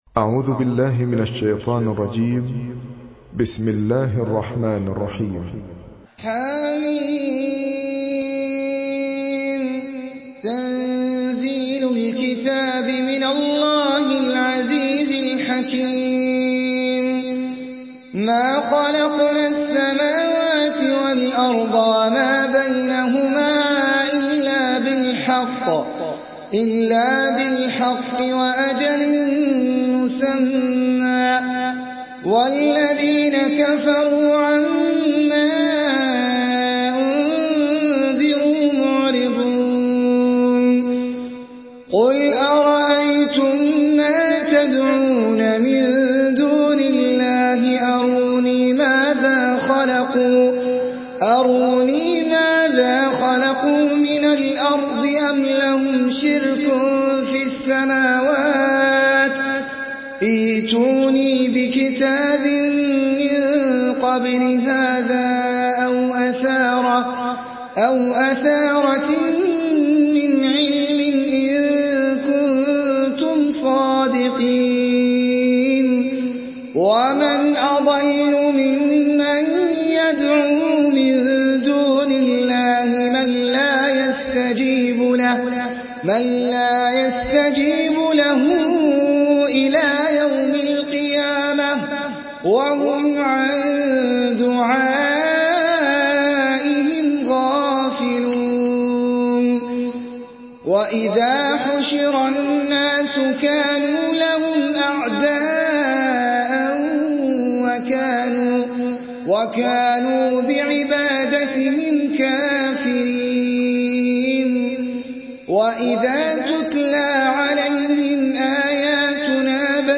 Surah الأحقاف MP3 in the Voice of أحمد العجمي in حفص Narration
مرتل